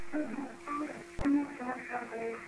Here's A Few EVP's Featuring Sing Song Voices & Music